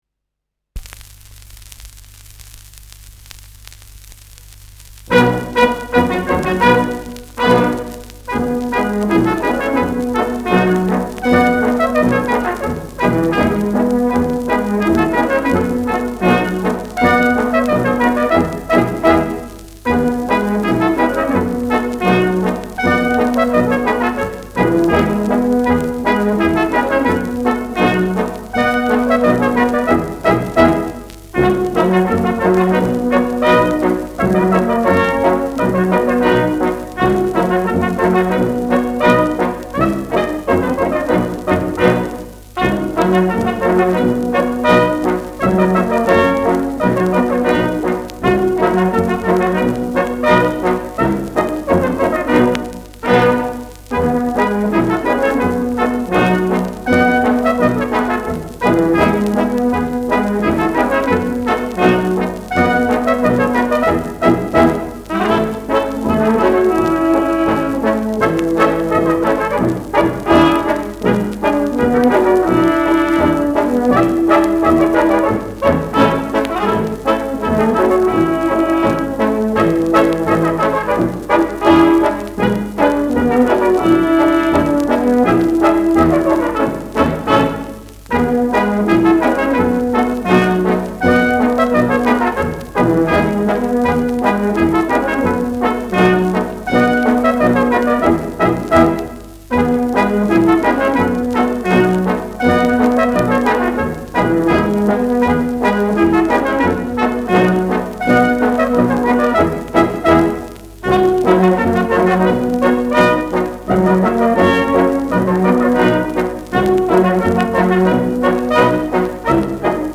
Schellackplatte
Knistern